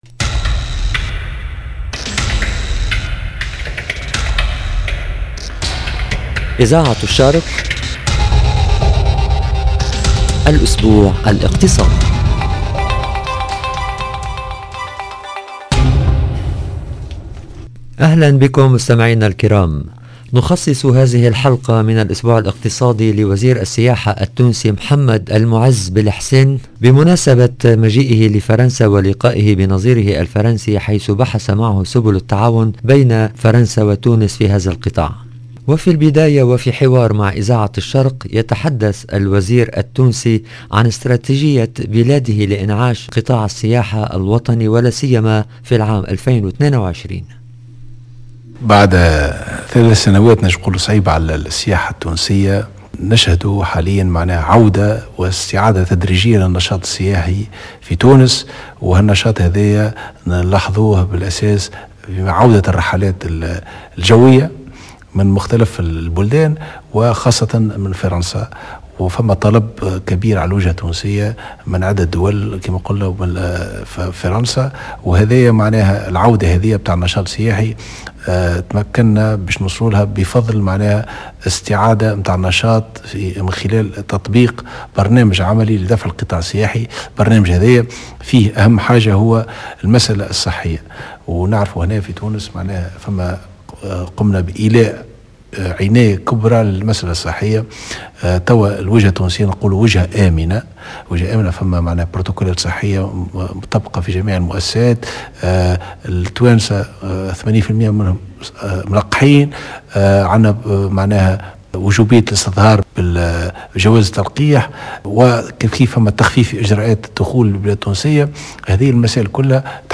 Économie Radio Orient cette semaine spéciale tourisme en Tunisie . On reçoit le ministre du tourisme tunisien Mohamad Moez Belhessin à l’occasion de sa visite à Paris et sa rencontre avec son homologue français . 0:00 10 min 38 sec